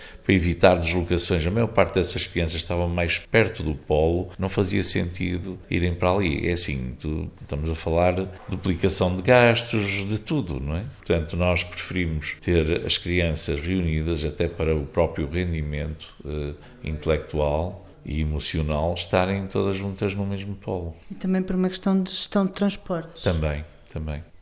Benjamim Rodrigues, presidente da câmara Municipal de Macedo de Cavaleiros explica que houve necessidade de agrupar estas crianças ao Polo 1, em Macedo de Cavaleiros, por diversas razões:
reuniao-de-camara-1.mp3